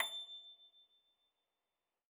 53l-pno25-A5.wav